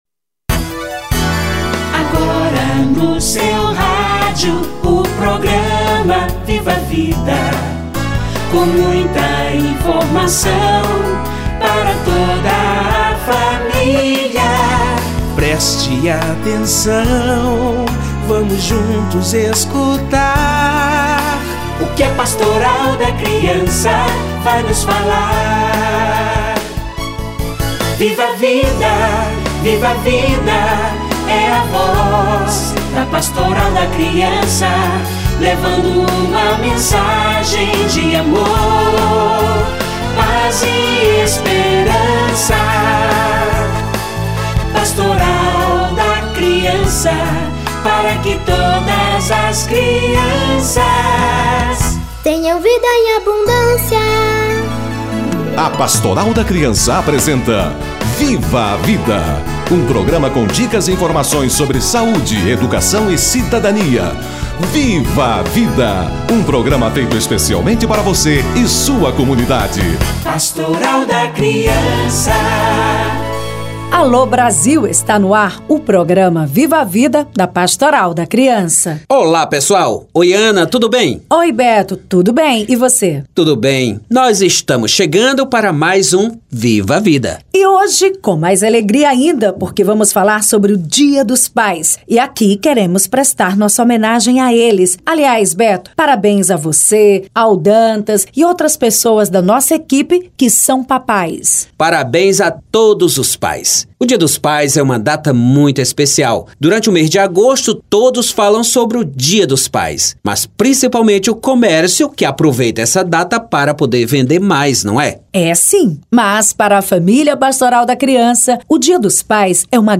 Dia dos pais - Entrevista